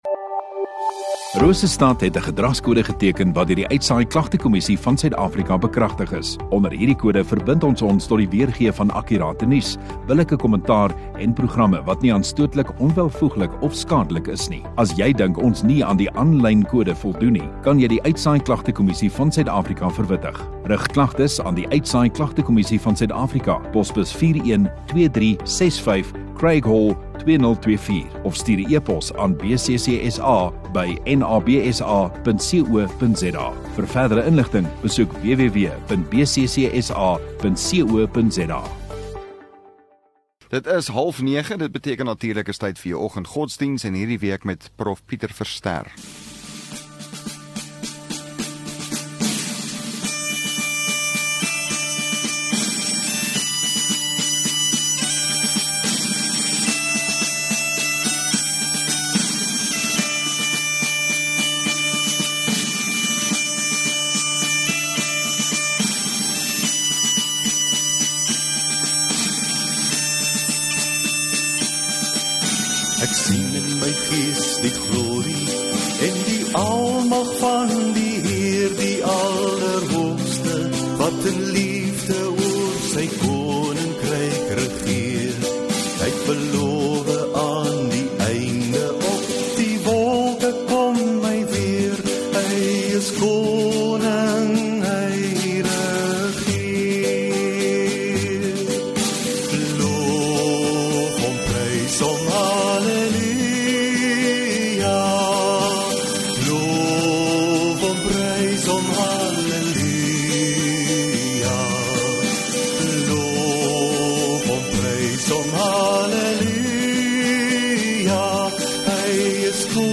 26 Mar Woensdag Oggenddiens